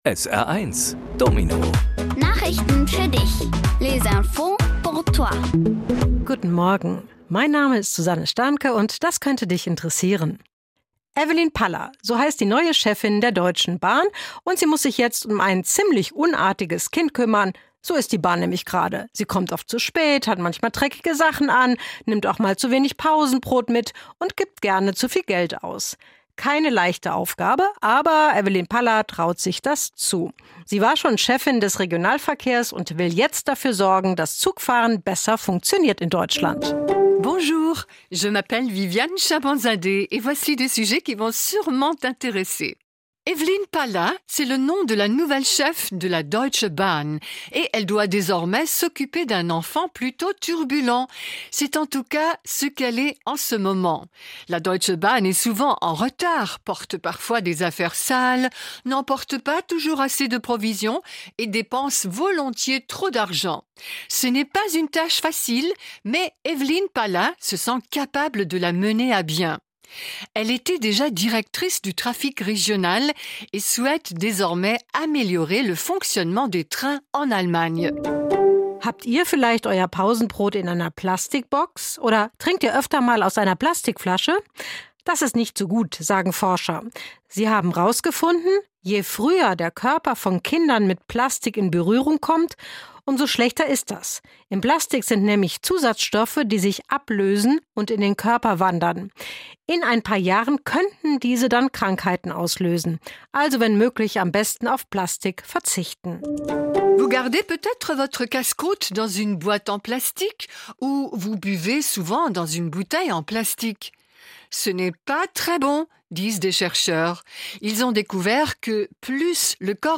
Kindgerechte Nachrichten auf Deutsch und Französisch:- Neue Bahn-Chefin- Plastik kann krank machen- Kulturpass gestrichen- Tour von Elch Emil beendet